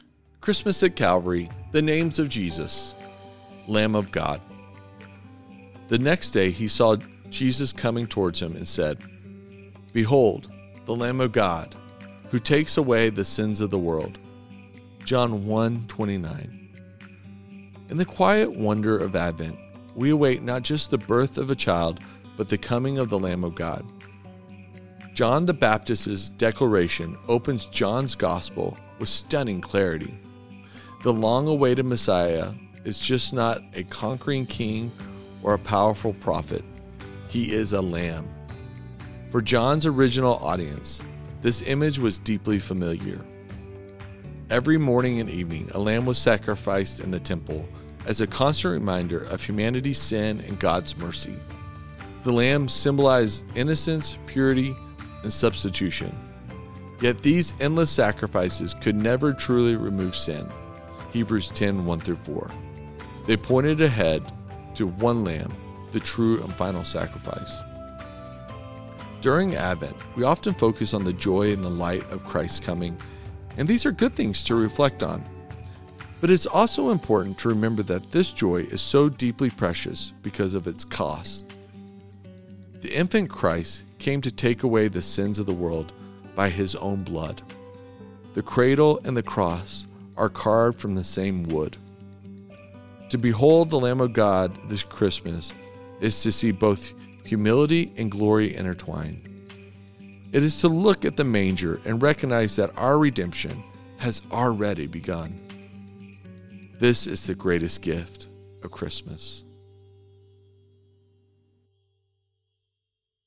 a conversation centered on one simple challenge: pay attention.